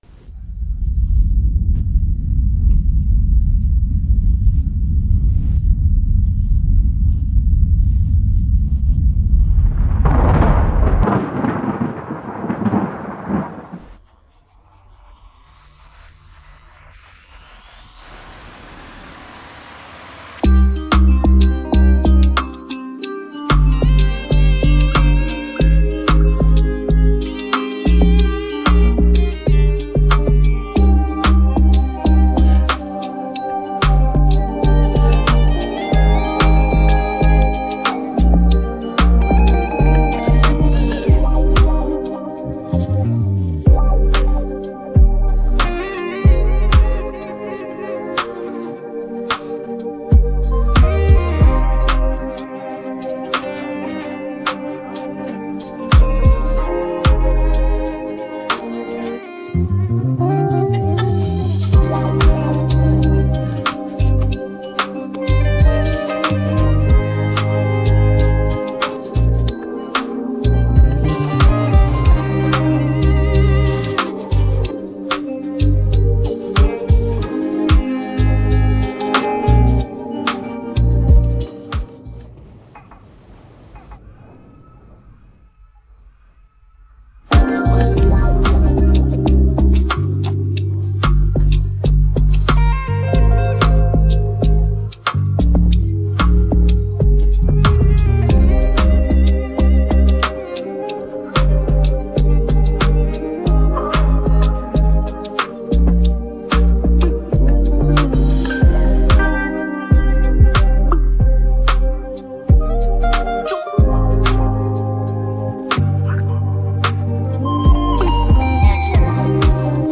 Afro popAfrobeats